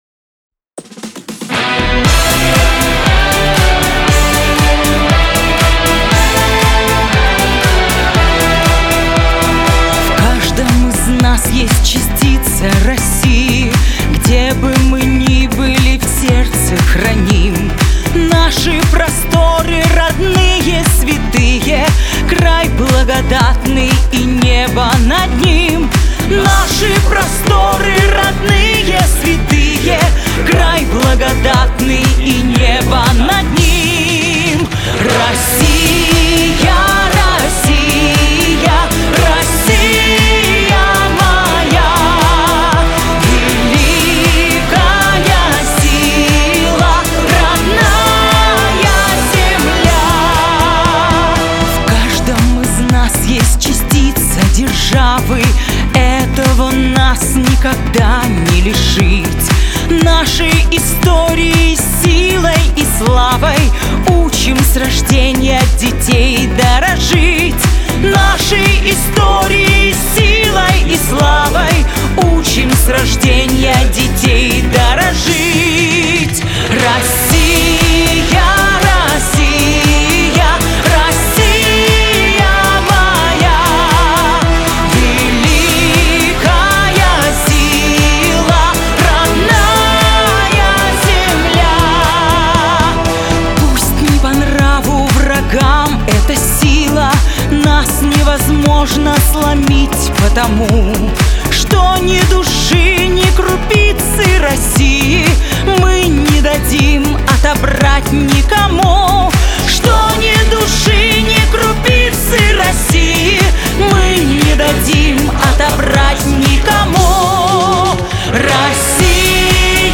• Категория: Детские песни
патриотическая